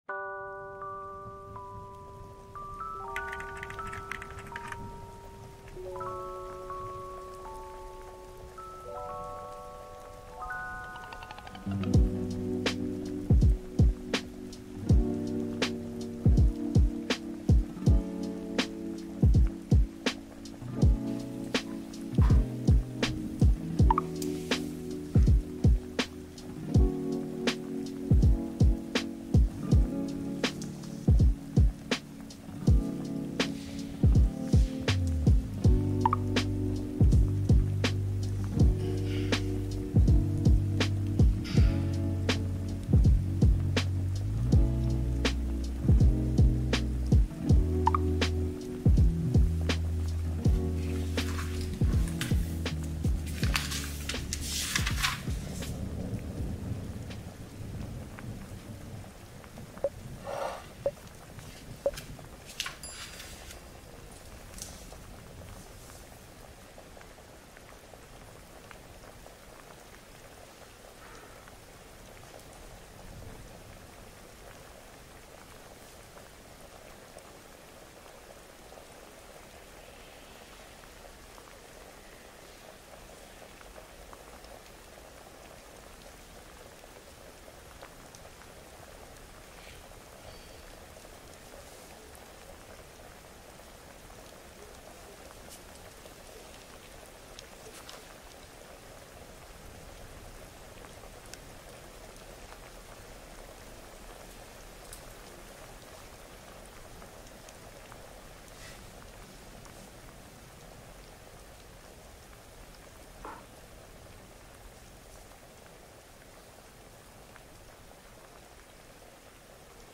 地球瞑想 – 7.83 Hz バイノーラルビート | シューマン共鳴瞑想